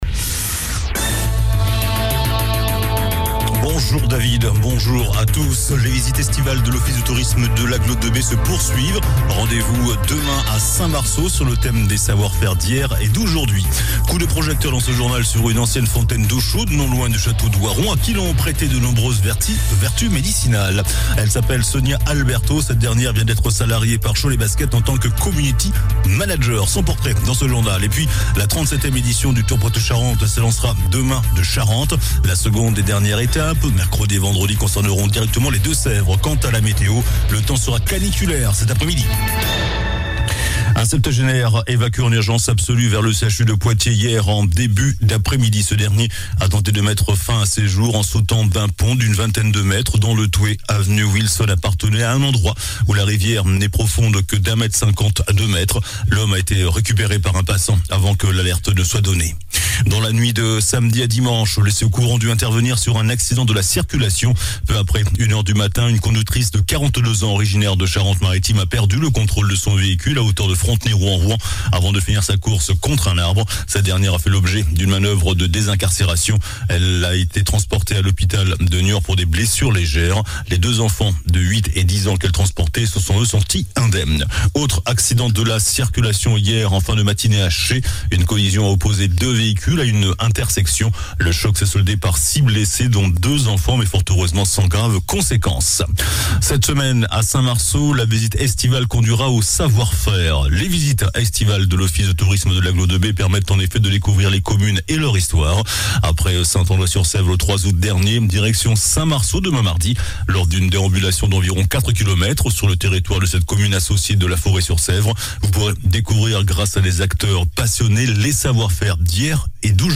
JOURNAL DU LUNDI 21 AOÛT ( MIDI )